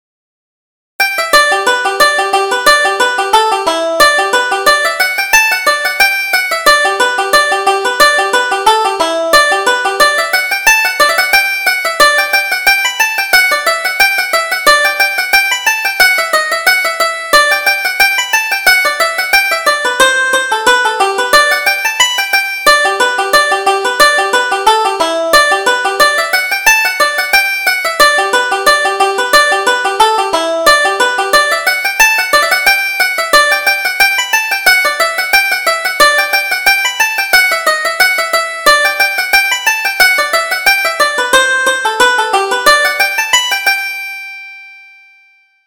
Reel: The Green Branch